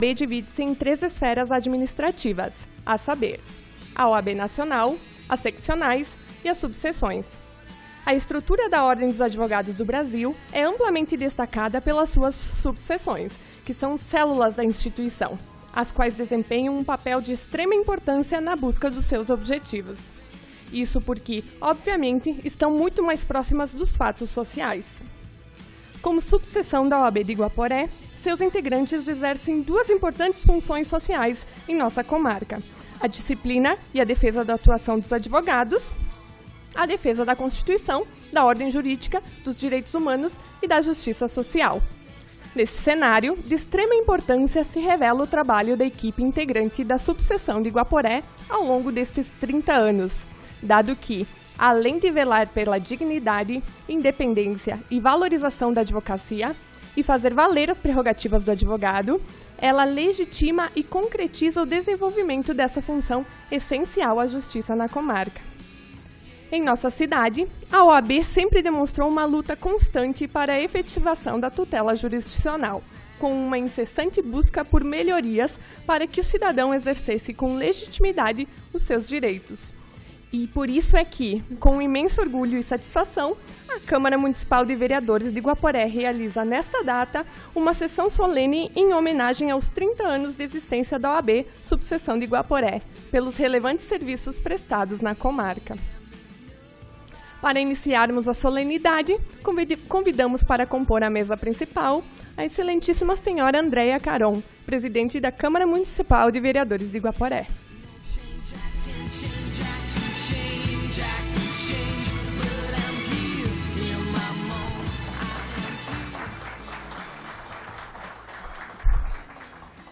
Sessão Solene em Homenagem aos 30 anos da OAB - Subseção Guaporé